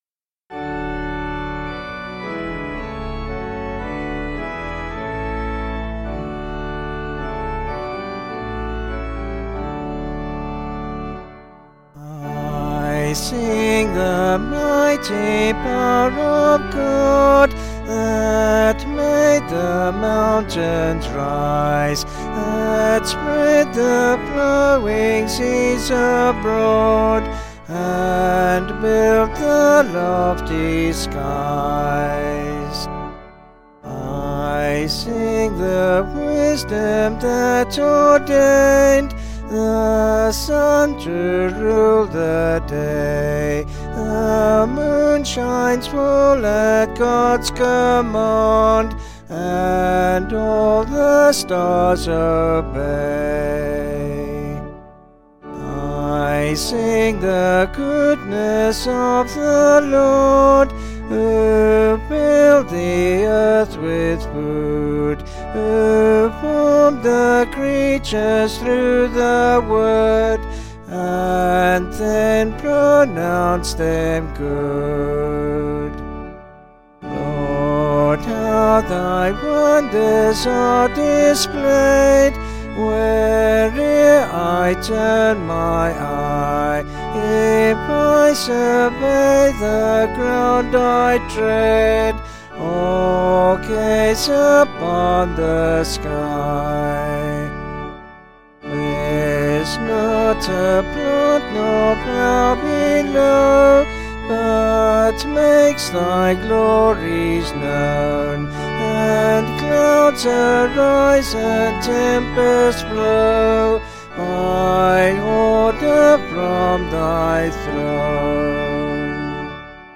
Vocals and Organ   264.5kb Sung Lyrics